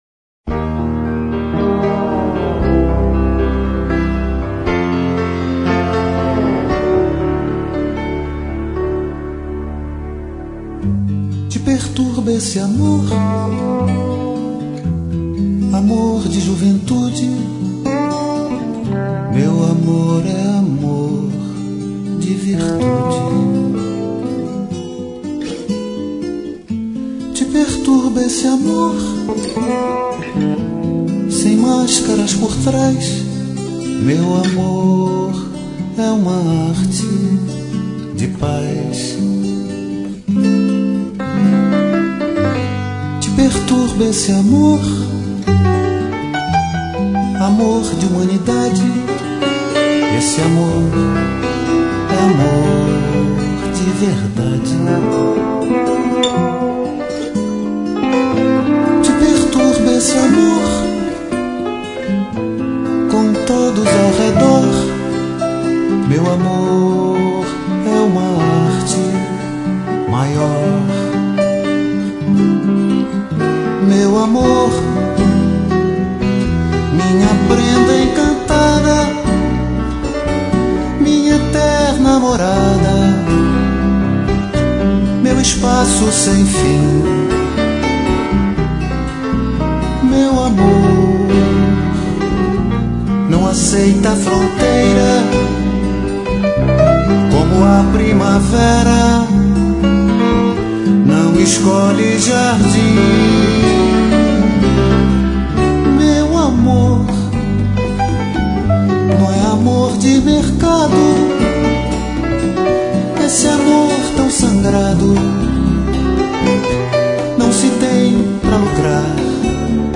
MPB